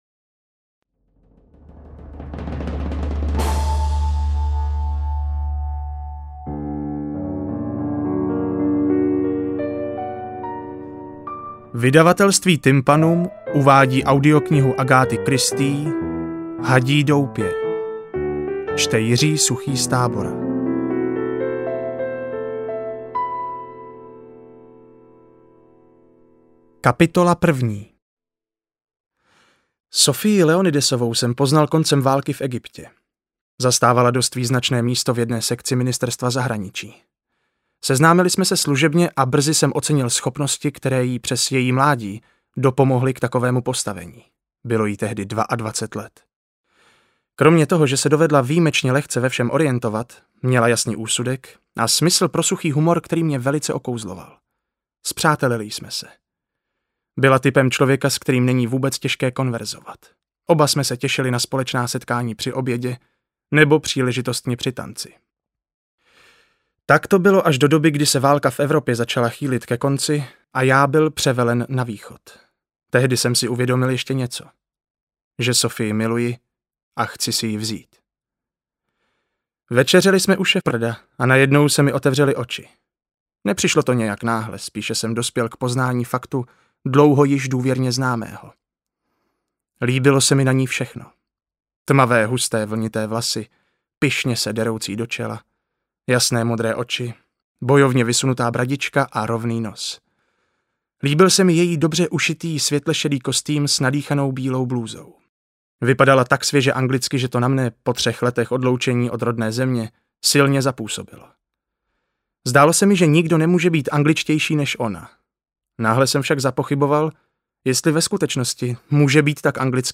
Interpret:  Jiří Suchý